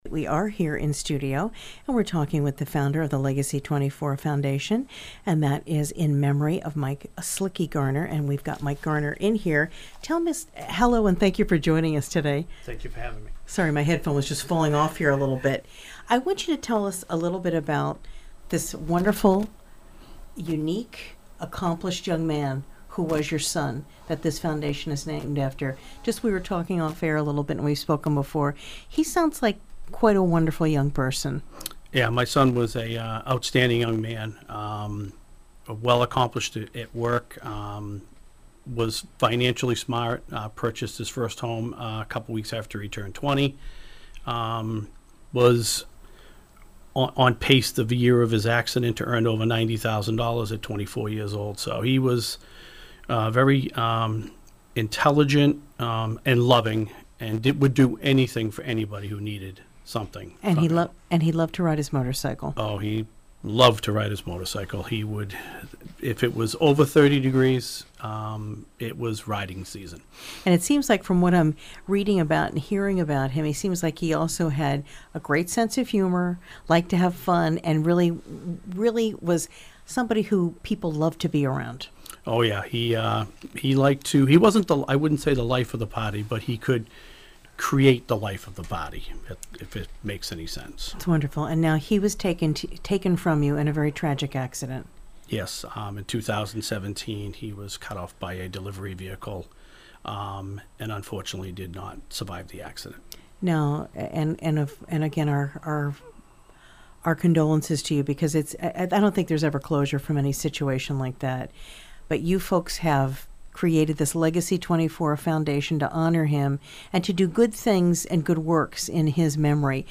— WATD 95.9 News & Talk Radio, South Shore Massachusetts